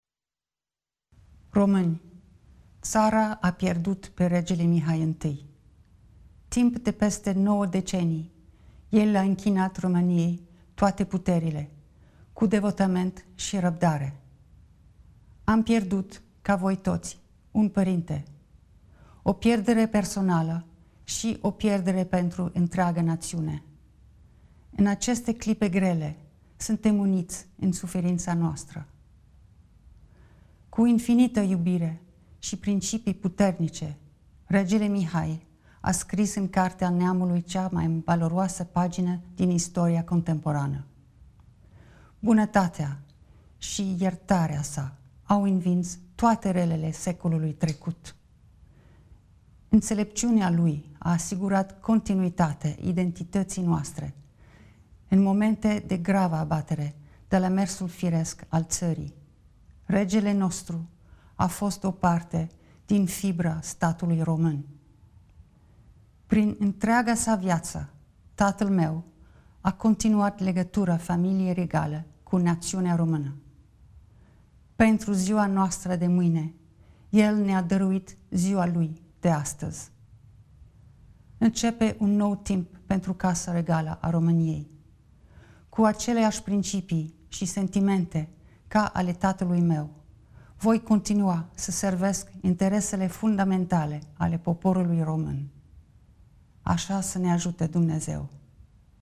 5-dec.-declaratia-noului-sef-al-familiei-regale-a-romaniei.mp3